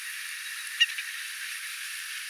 jonkinlainen tundrahanhen
erikoinen ääni ilmeisesti
ehka_jonkinlainen_tundrahanhen_yksittainen_aani.mp3